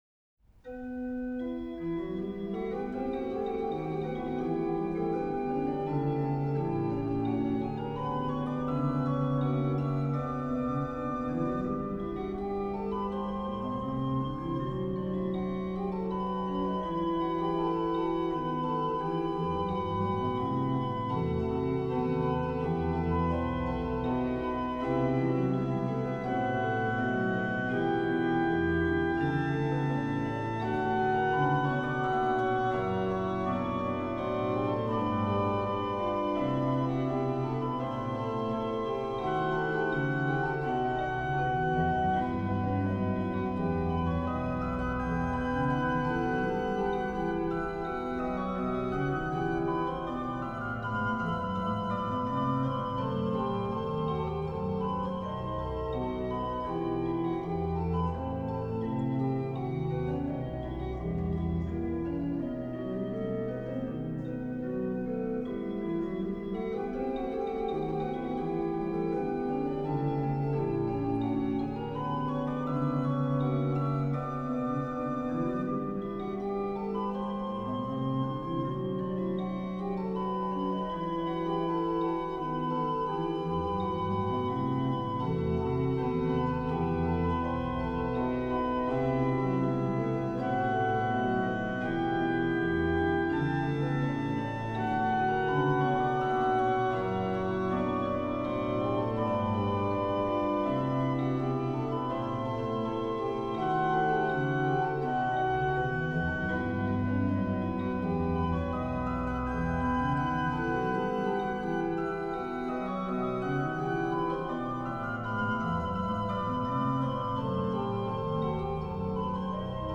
Bach Organ Works
Subtitle   il canto fermo nel pedale
Venue   1755 Gottfried Silbermann/Zacharias Hildebrandt organ, Kathedrale, Dresden, Germany
Registration   BW: 8Ged, 4Rfl
Ped: HW/Ped; HW: Oct4, Spz4, Oct2